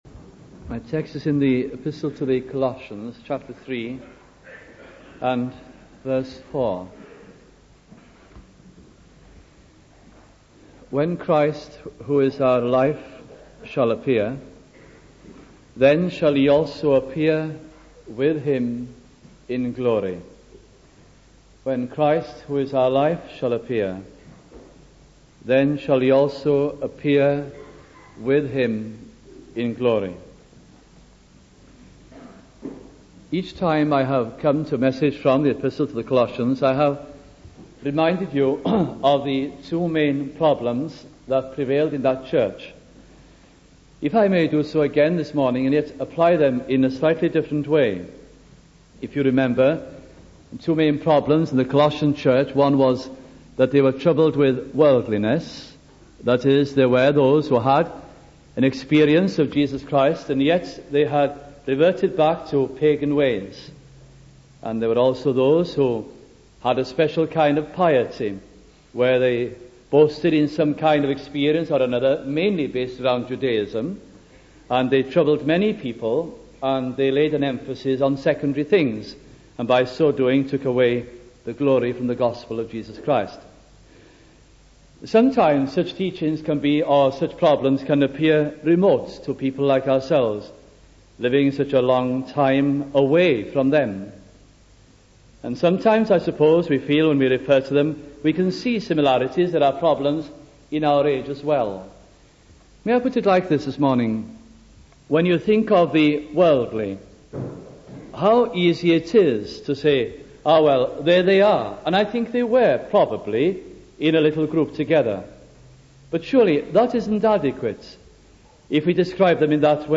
» Colossians Series 1973 » Please note that due to missing parts of the historic audit of recordings this series is incomplete » We also regret that a few sermons in this series do not meet the Trust's expectations of the best sound quality.